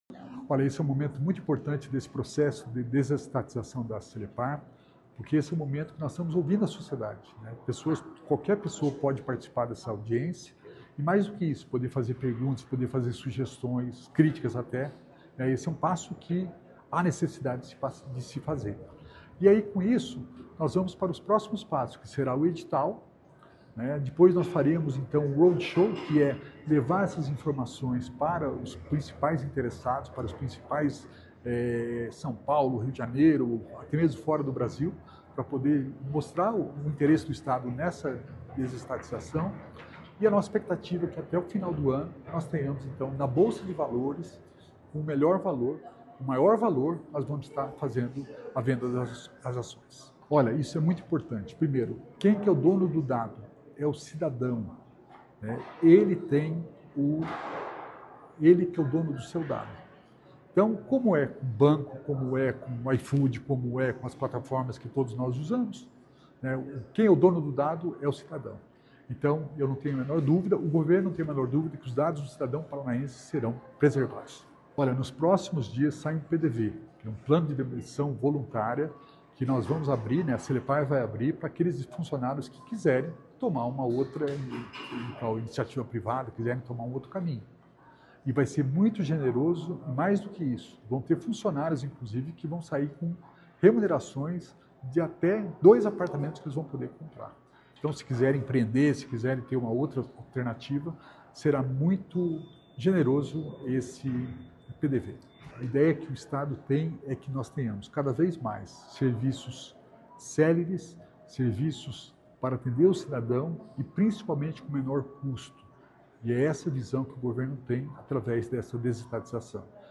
Sonora do secretário da Inovação e Inteligência Artificial, Alex Canziani, sobre a audiência pública que apresentou detalhes da desestatização da Celepar